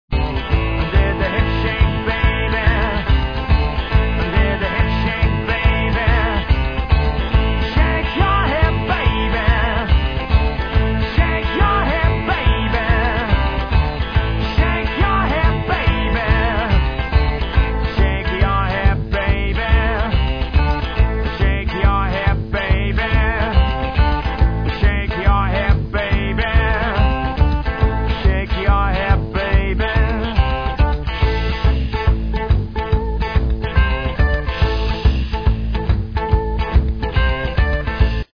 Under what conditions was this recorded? All samples are 8bit 11KHz mono recordings